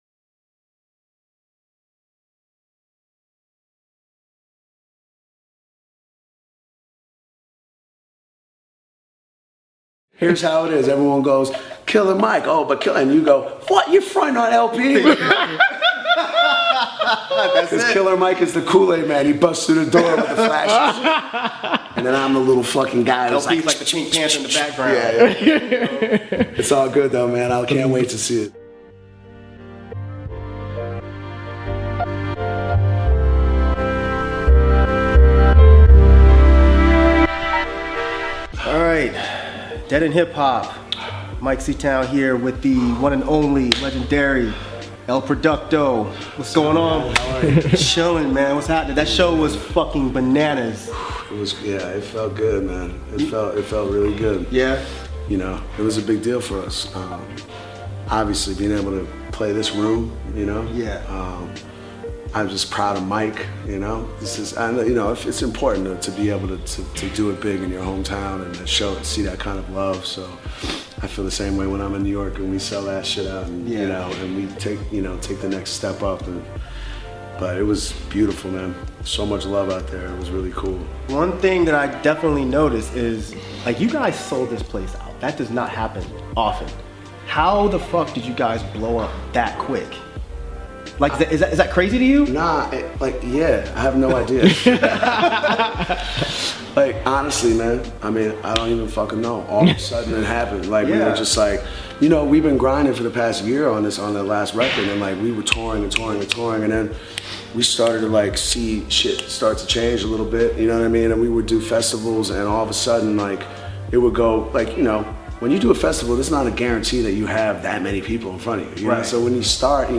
Run the Jewels 2 Interview with El-P | DEHH